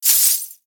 Danza árabe, bailarina da un golpe de cadera con un pañuelo de monedas 04
Sonidos: Especiales
Sonidos: Acciones humanas